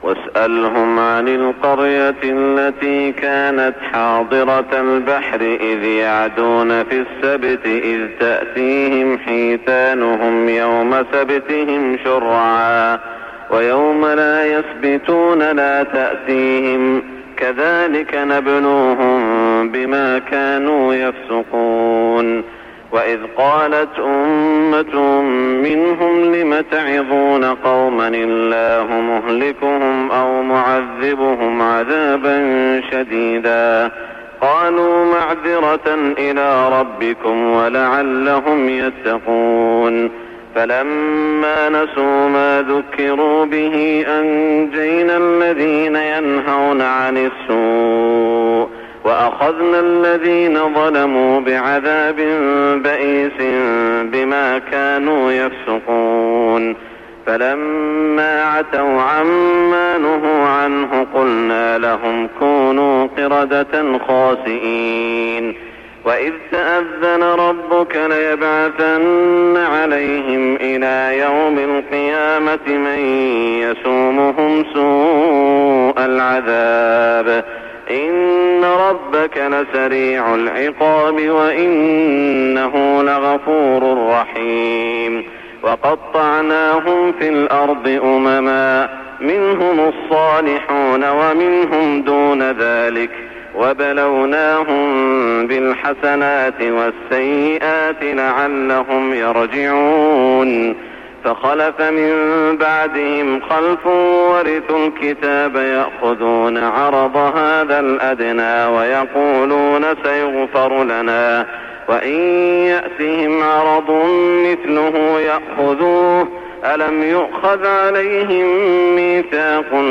صلاة الفجر 1423هـ من سورة الأعراف > 1423 🕋 > الفروض - تلاوات الحرمين